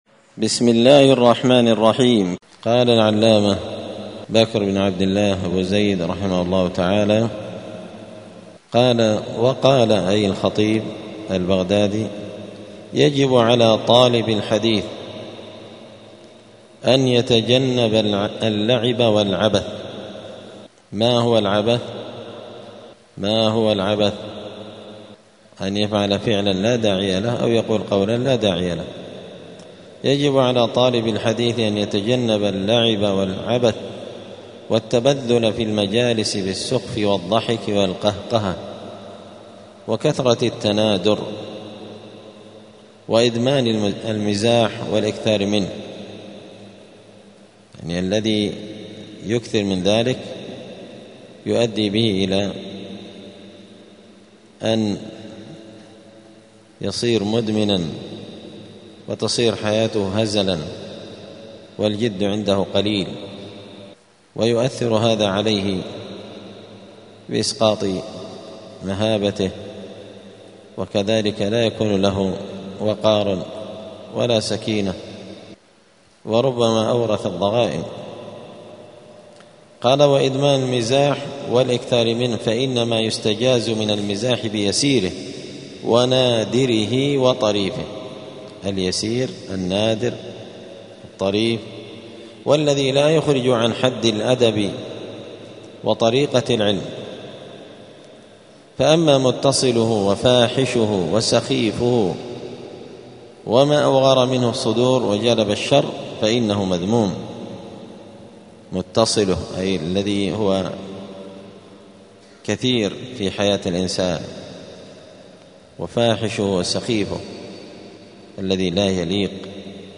الخميس 3 ذو القعدة 1446 هــــ | الدروس، حلية طالب العلم، دروس الآداب | شارك بتعليقك | 16 المشاهدات